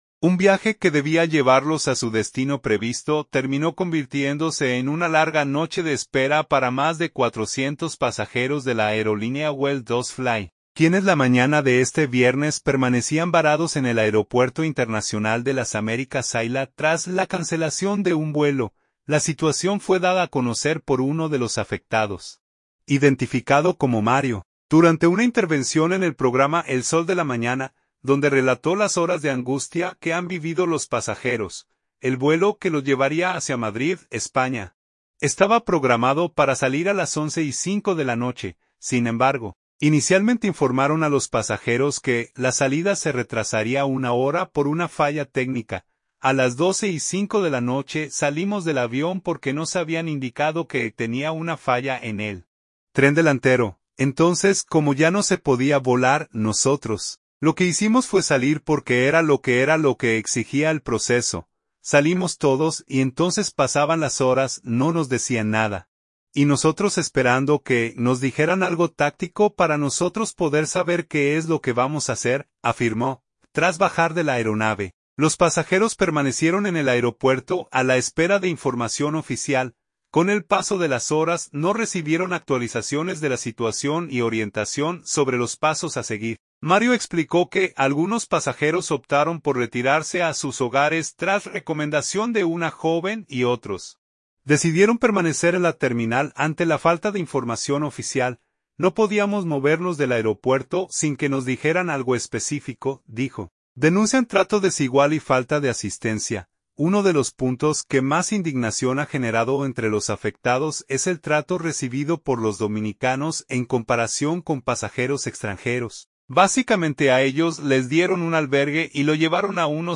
durante una intervención en el programa El Sol de la Mañana